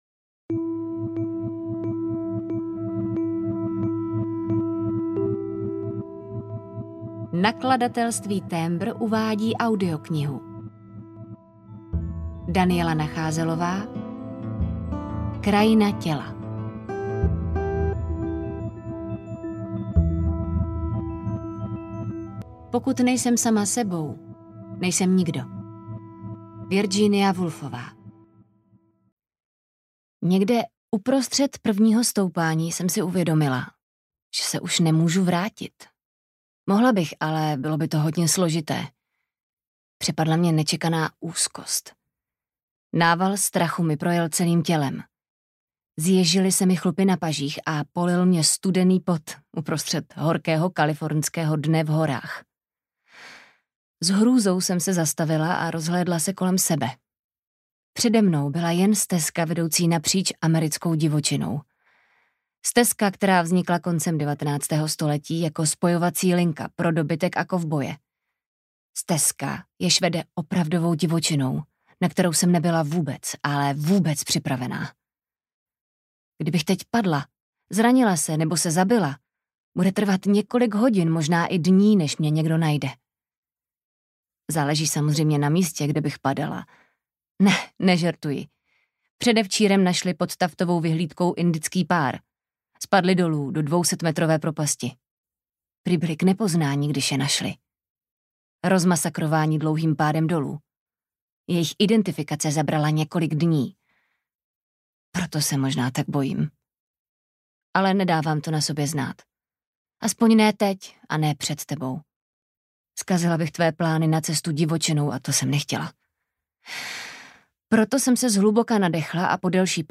Krajina těla audiokniha
Ukázka z knihy